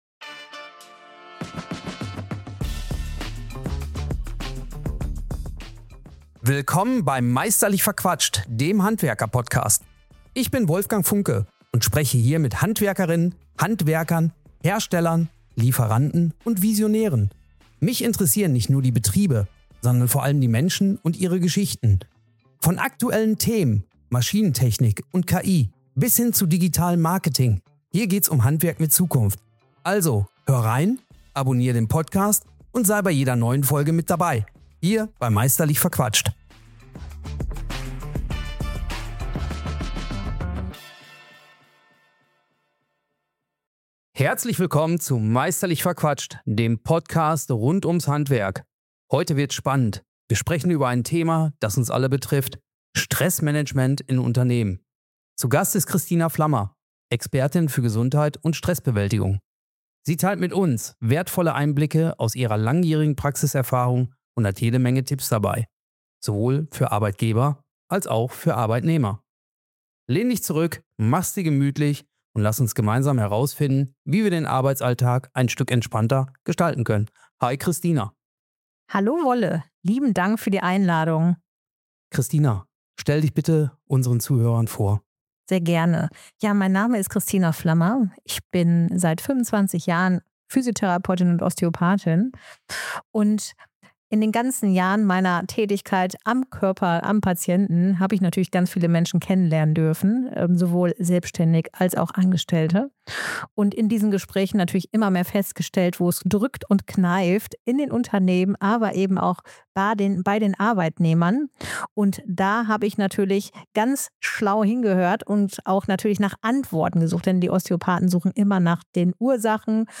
Ein inspirierendes Gespräch voller Fachwissen, Alltagsnähe und echter Denkanstöße – für alle, die Arbeit, Gesundheit und innere Balance besser miteinander verbinden möchten. https